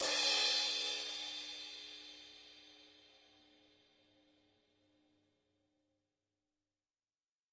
描述：中号中国镲片 Sabian AA Chinese